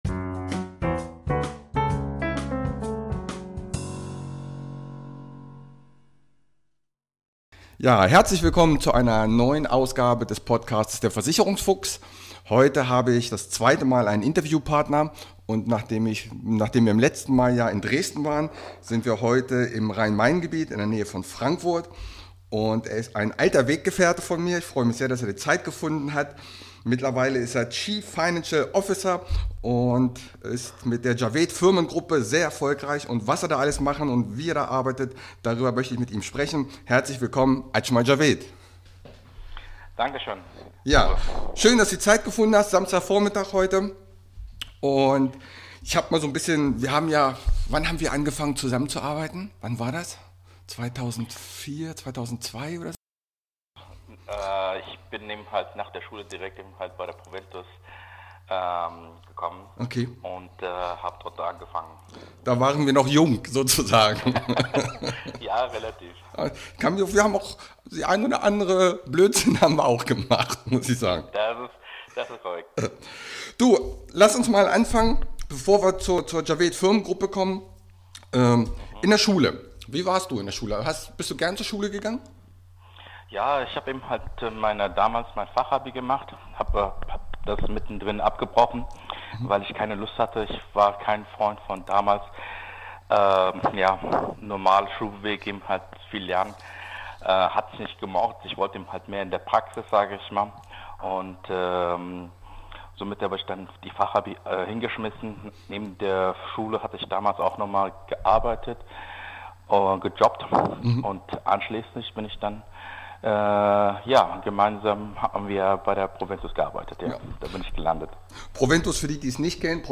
# 035 SONDERFOLGE INTERVIEW